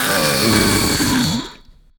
burer_die_0.ogg